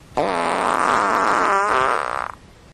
complaining fart
fart flatulation flatulence gas poot sound effect free sound royalty free Memes